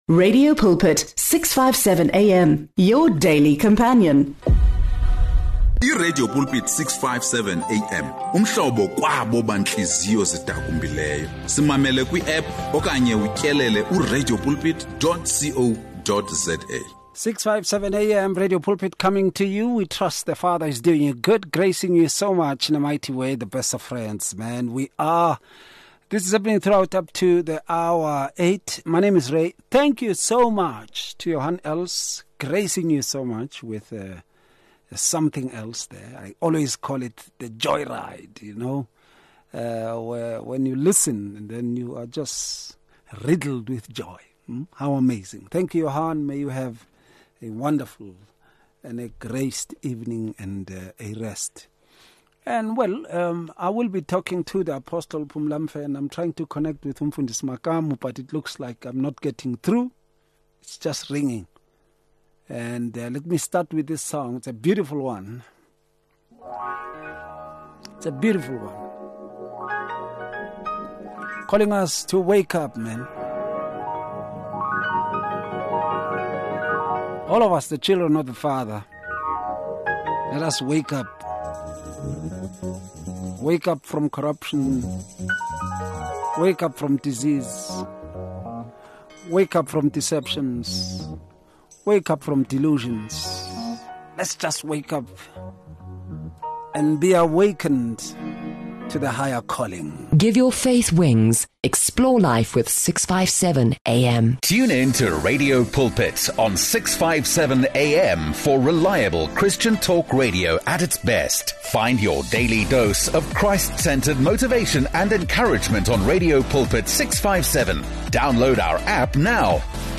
The discussion encourages believers to examine their motives and renew their commitment to wholehearted service.